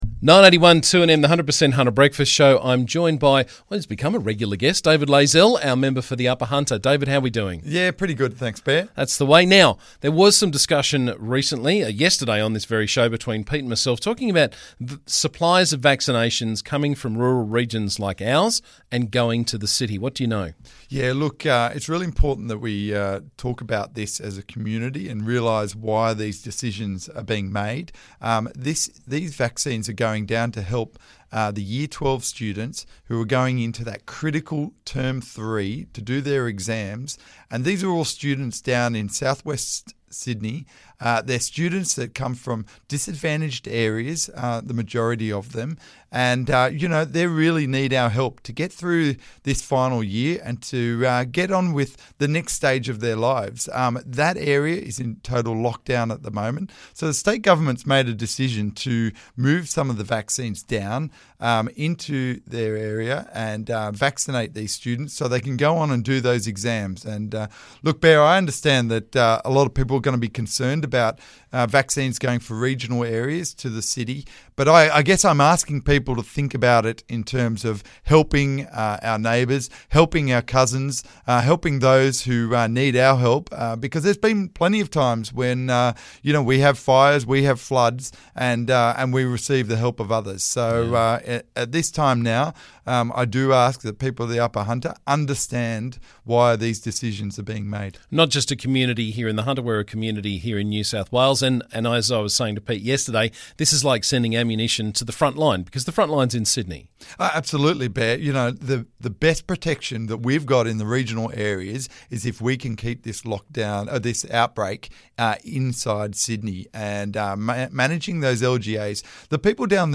Member for Upper Hunter David Layzell was on the show this morning to talk about sharing our vaccine supplies and mine rehabilitation.